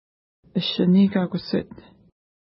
ID: 305 Longitude: -59.5367 Latitude: 53.1409 Pronunciation: əʃəni: ka:kusset Translation: Where a Rock is Fishing Feature: lake Explanation: There is a rock there that looks like a human kneeling down ice fishing.